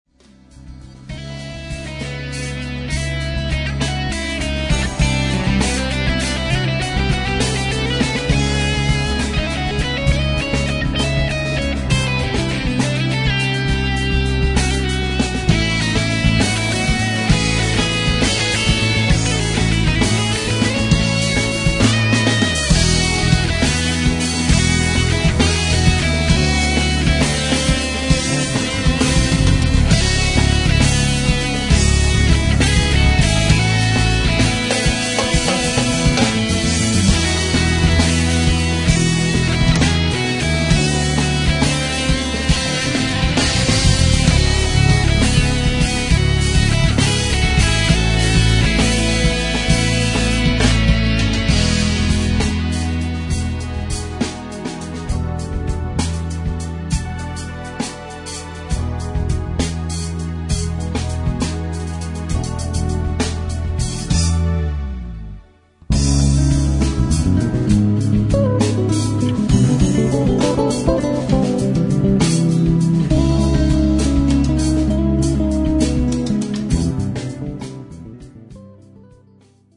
痛快プログレフュージョン2002年傑作、ドライブのＢＧＭにどうぞ！
guitar, classical guitar
drums
keyboards
violin
congas, cajon, shakers, chimes
フュージョンバンドだと思います。